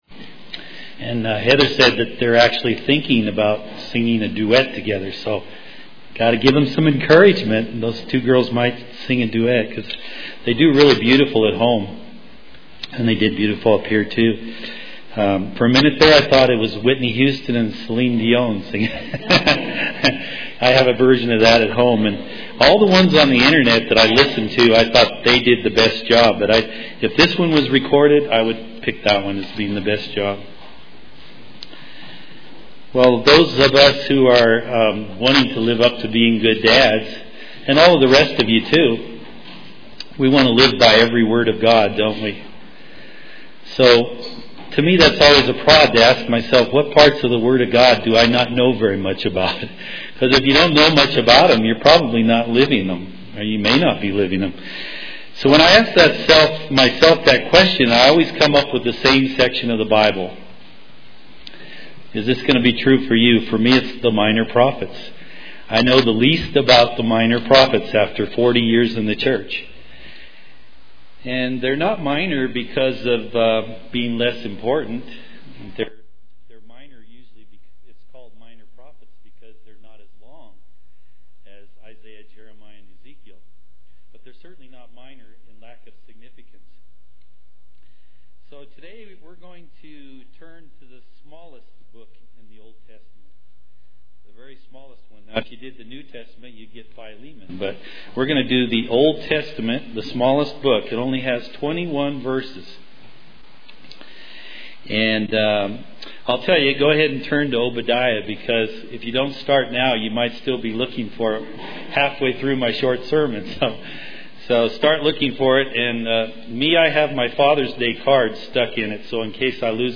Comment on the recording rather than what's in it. Given in Colorado Springs, CO